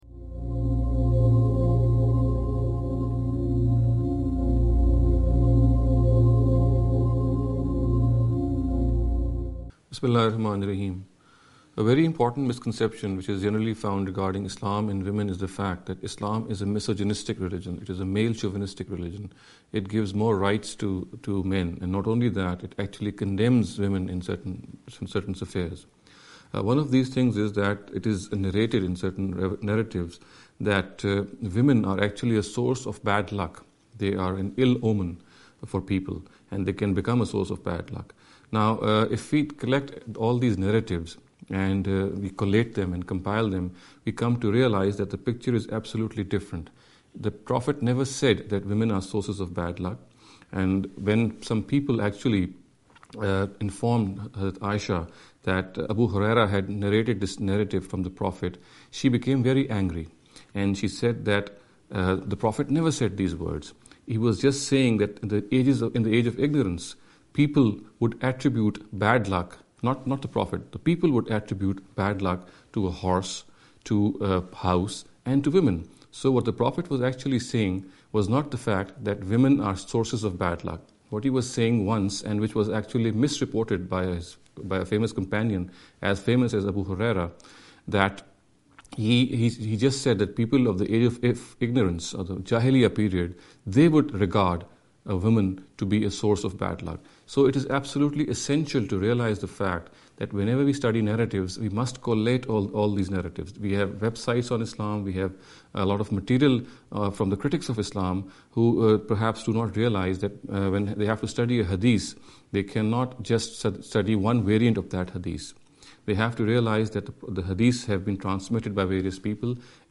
This lecture series will deal with some misconception regarding the Islam & Women.